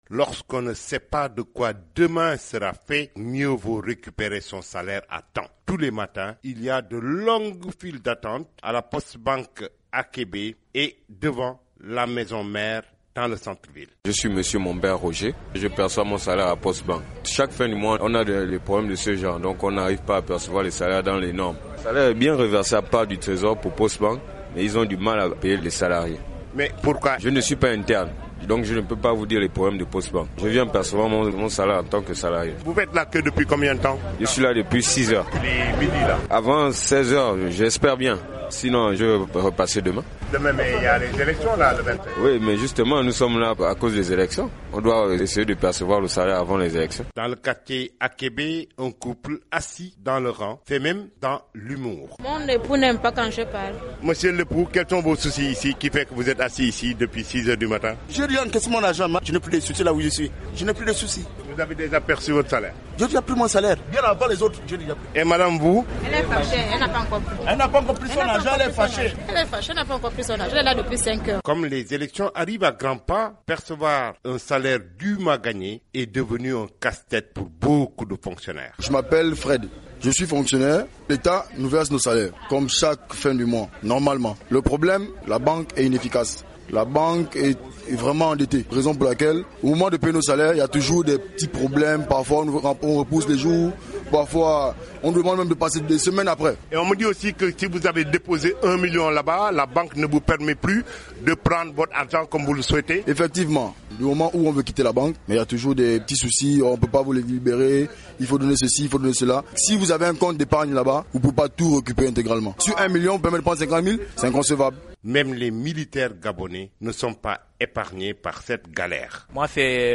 C'est donc la ruée vers les banques pour percevoir les salaires. Pour ceux qui sont à la Postebank de Libreville, l'attente peut être longue...
Reportage